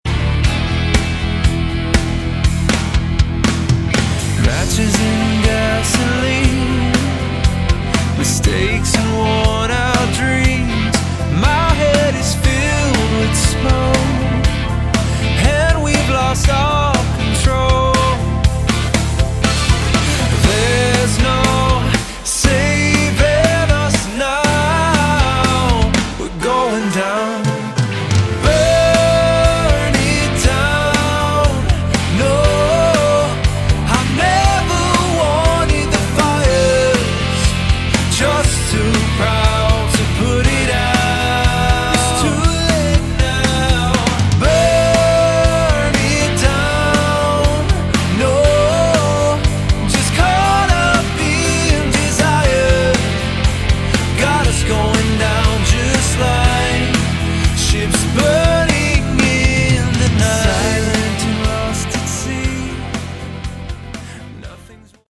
Category: Light AOR
guitars, keyboards
vocals
bass
drums